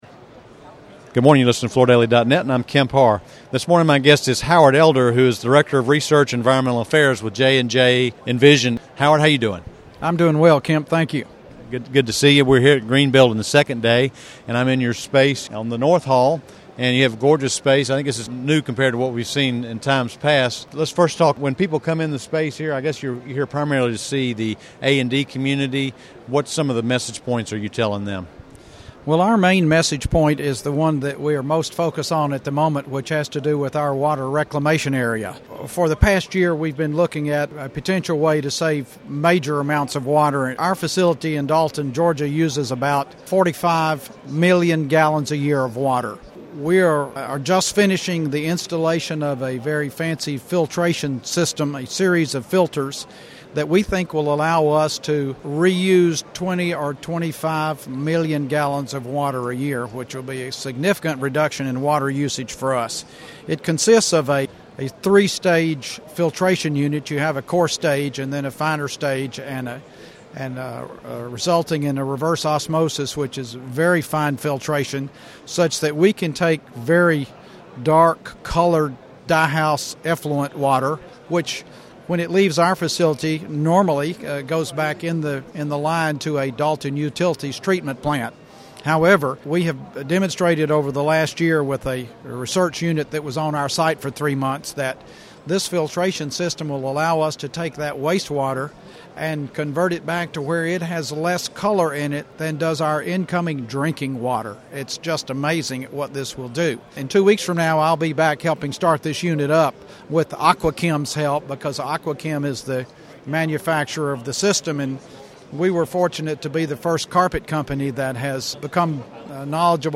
from Greenbuild 2011